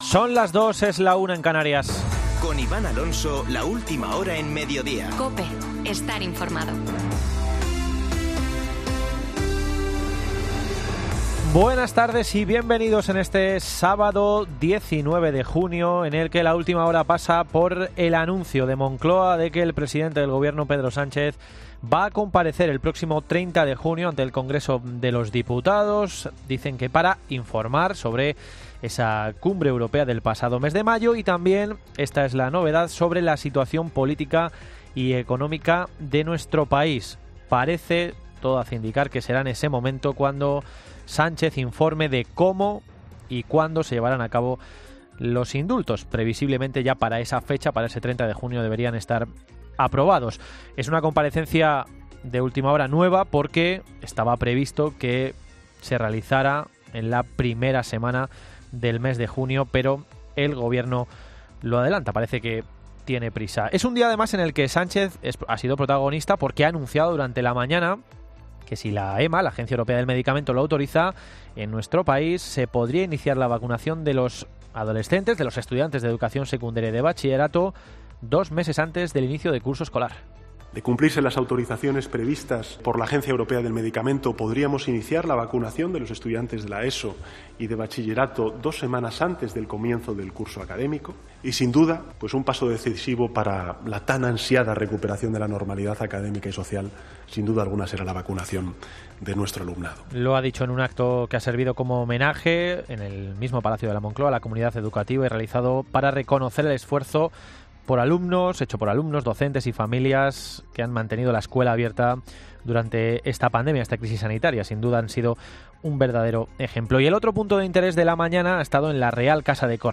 Boletín de noticias COPE del 19 de junio de 2021 a las 14.00 horas